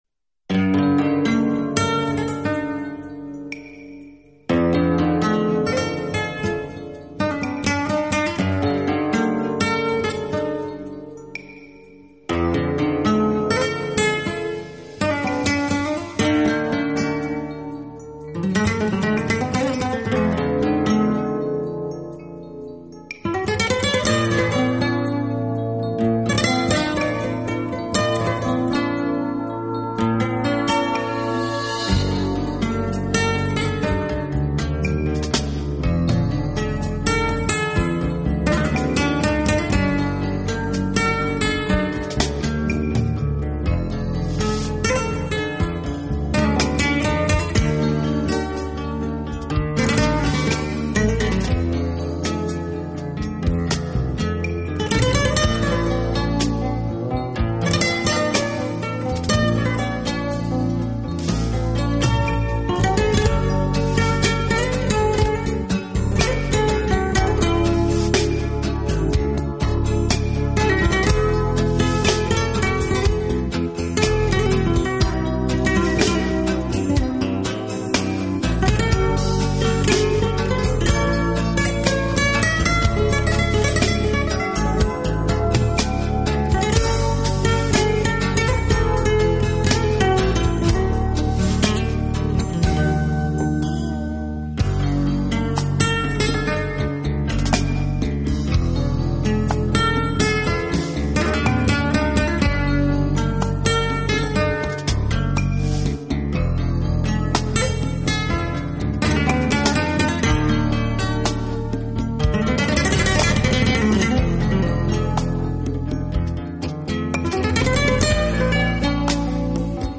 Genre: Latin / Flamenco Rumba
Genre: Flamenco Rumba (弗拉明戈伦巴)，Latin (拉丁)